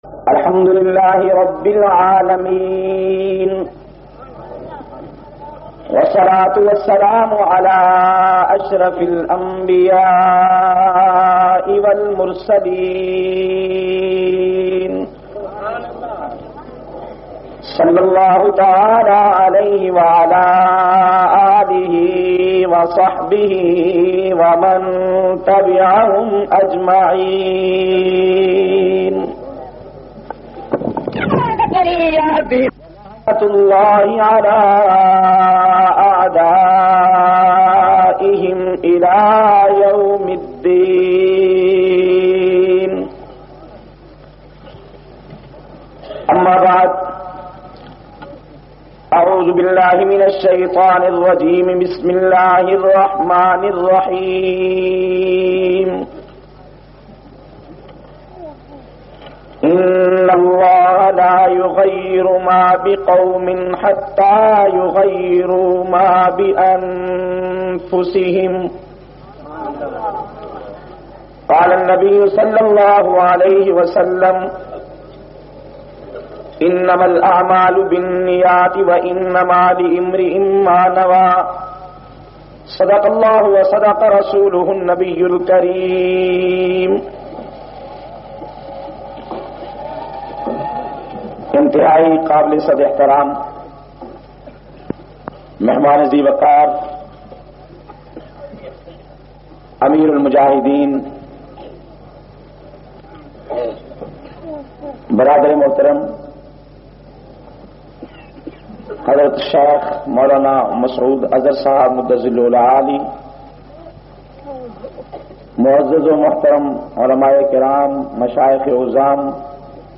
296- Islah e Muashira Jumma Jhang.mp3